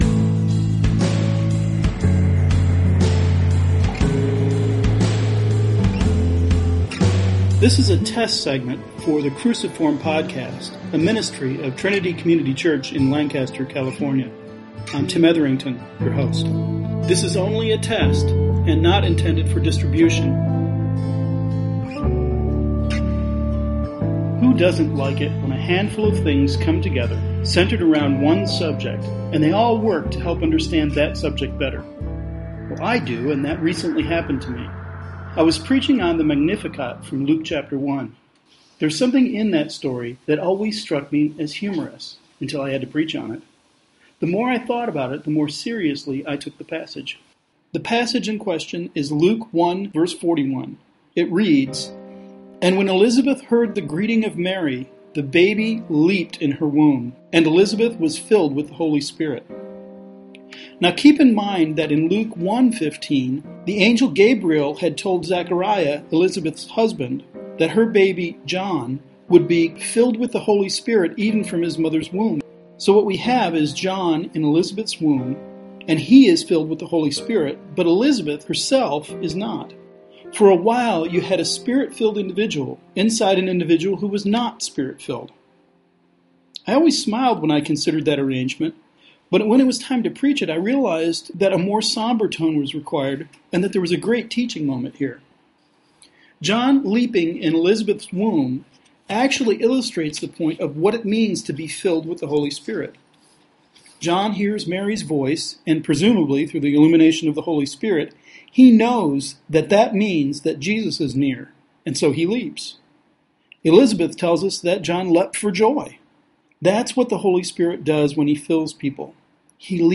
This is a test run of a segment for the potential, future podcast Cruciform. The production value of this audio segment is not great; I didn’t record it to test that but to see if the content would be sufficient and if my voice is not too annoying.